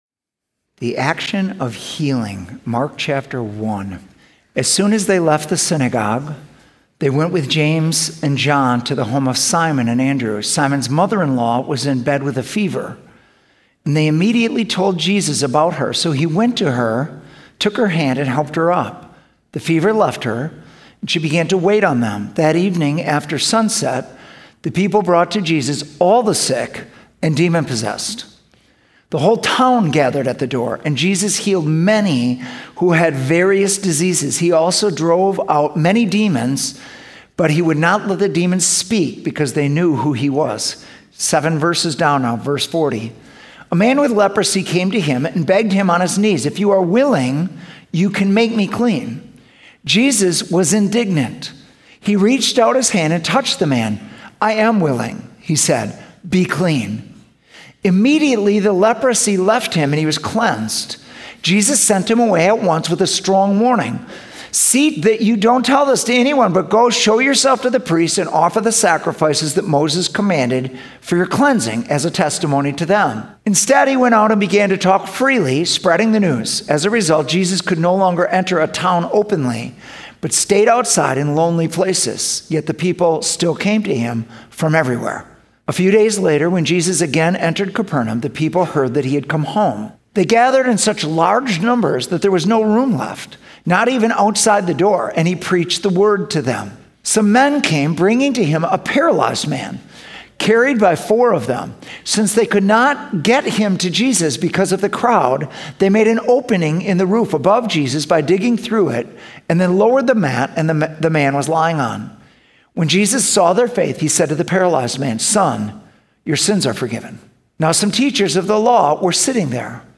Sunday Messages @ City Church